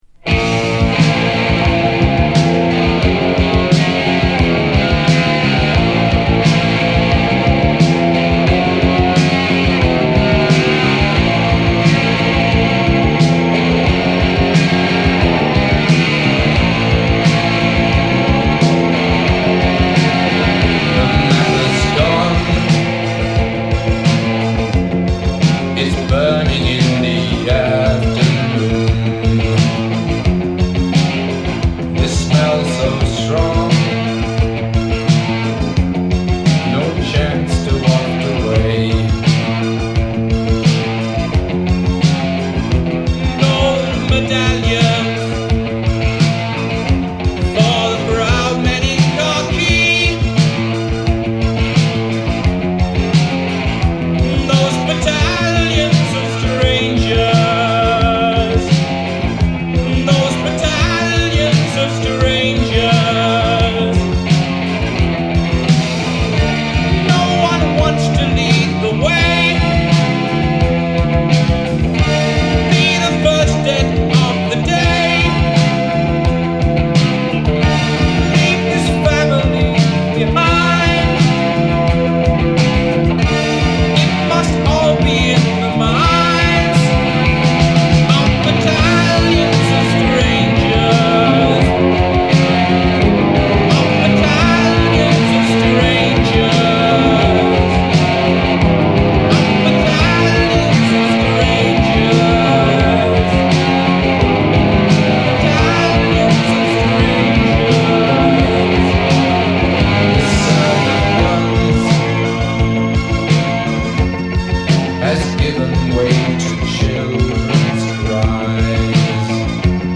groupe pionnier de la new-wave